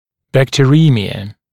[ˌbæktəˈriːmɪə][ˌбэктэˈри:миэ]бактериемия (наличие бактерий в циркулирующей крови)